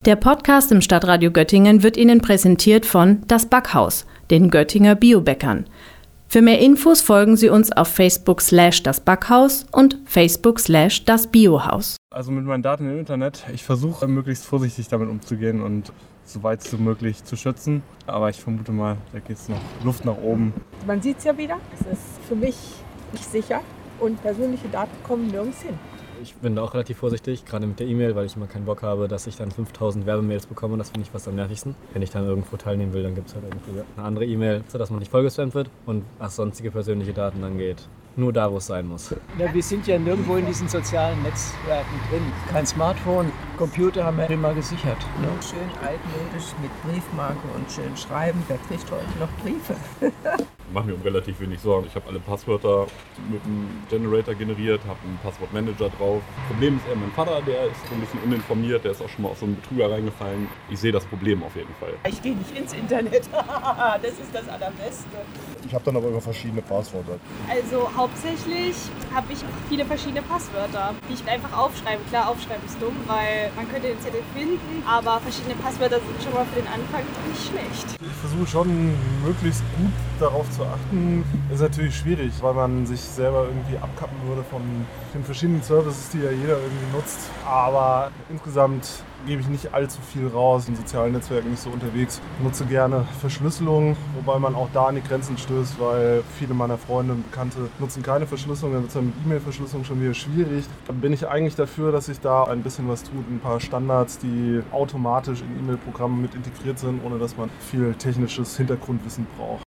Sicherheit im Internet ist das brandaktuelle Thema unserer neusten Umfrage.